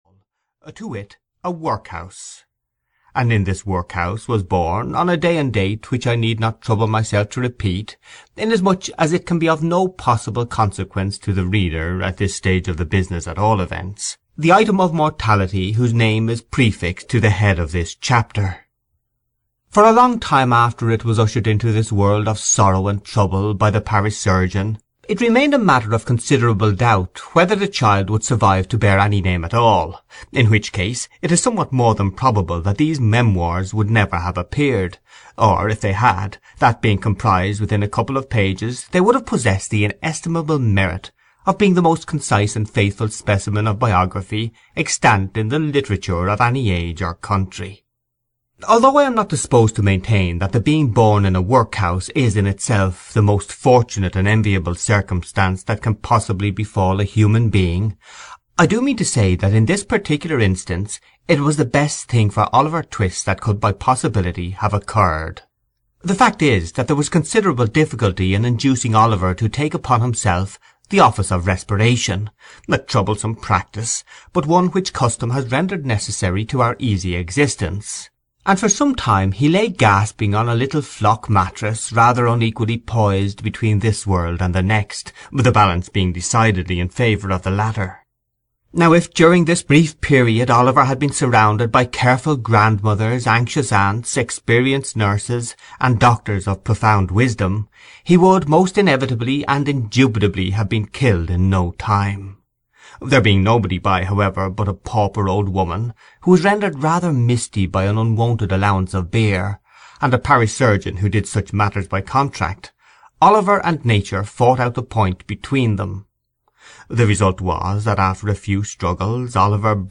Oliver Twist (EN) audiokniha
Ukázka z knihy